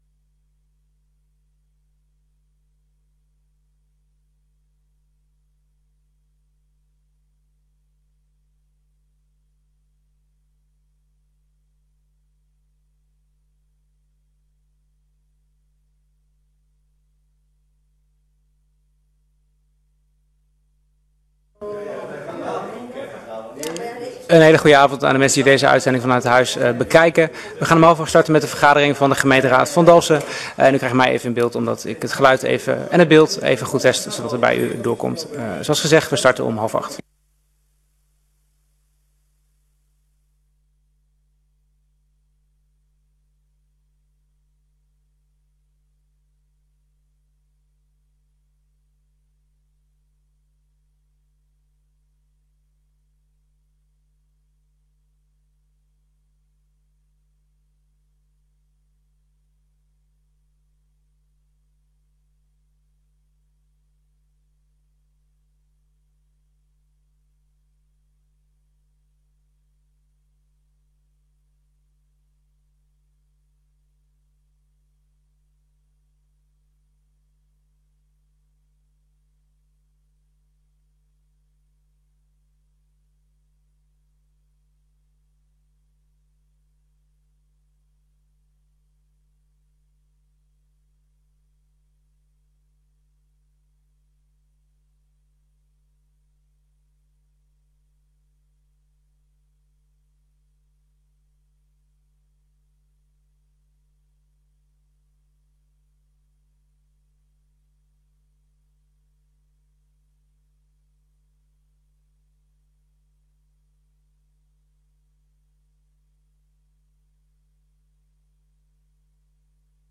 Gemeenteraad 02 juni 2025 19:30:00, Gemeente Dalfsen
Download de volledige audio van deze vergadering